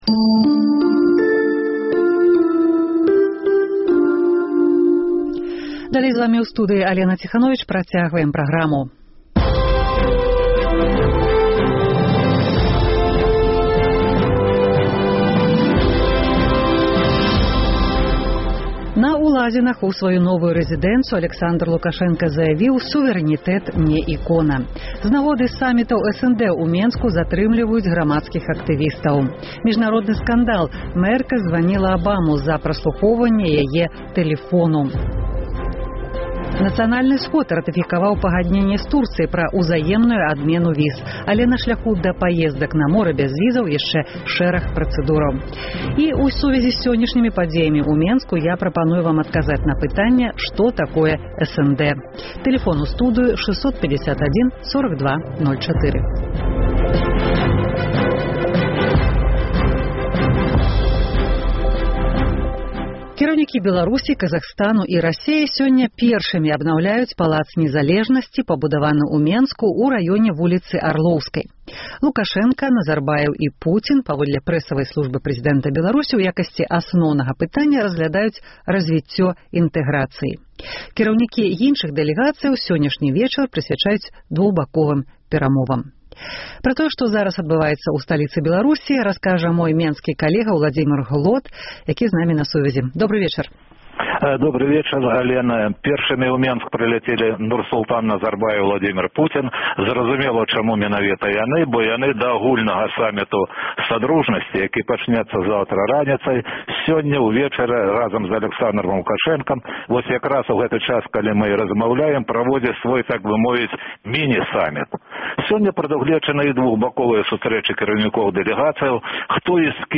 Зьлёт лідэраў СНД у Менску : што важнага вырашыцца для беларусаў? Vox populi: Што такое СНД? Палата прадстаўнікоў ратыфікавала міжурадавае пагадненьне з Турэччынай аб бязьвізавым рэжыме.
Камэнтар эканаміста.
Як шафёры ставяцца да новых правілаў пакараньня п’яных за рулём – галасы аўтааматараў Гомеля.